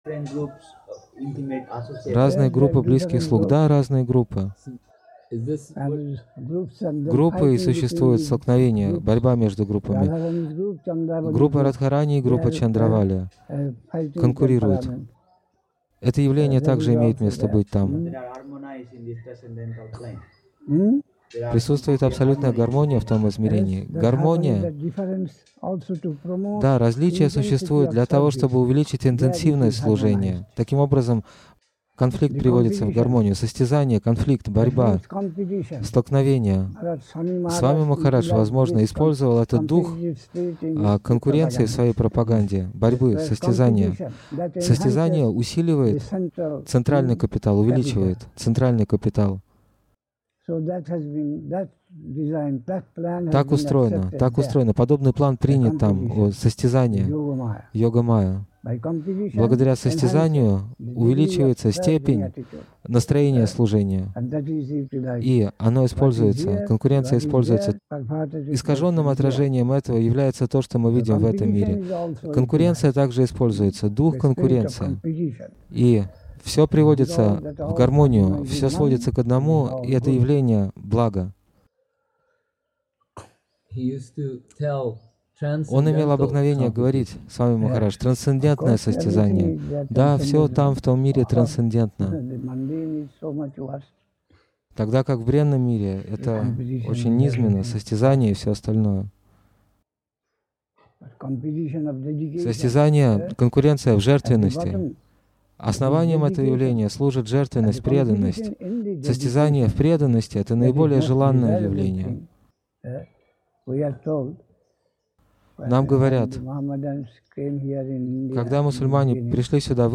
(15 февраля 1982 года. Навадвипа Дхама, Индия)